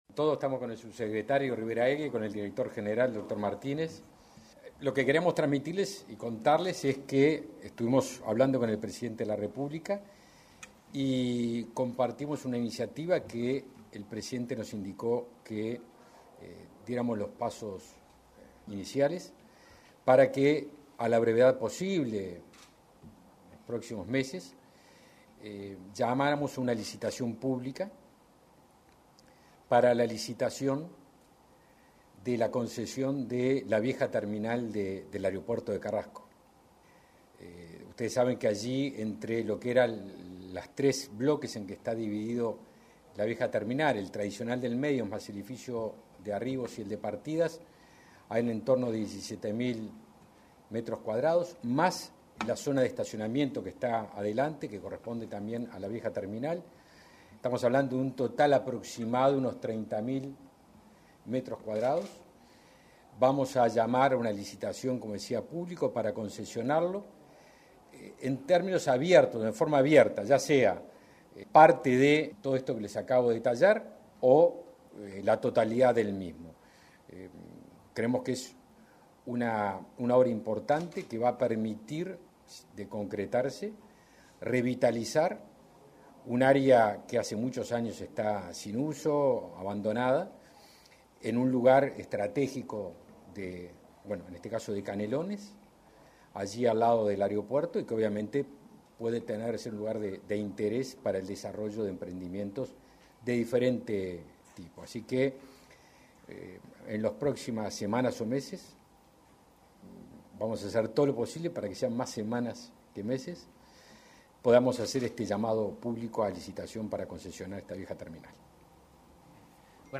Declaraciones del ministro de Defensa Nacional, Javier García, y del director general de Secretaría, Fabián Martínez
Declaraciones del ministro de Defensa Nacional, Javier García, y del director general de Secretaría, Fabián Martínez 04/08/2021 Compartir Facebook X Copiar enlace WhatsApp LinkedIn Este miércoles 4, en la Torre Ejecutiva, el ministro de Defensa Nacional, Javier García, y el director general de Secretaría del mencionado ministerio, Fabián Martínez, brindaron declaraciones a la prensa, tras finalizar acuerdos con el presidente de la República, Luis Lacalle Pou.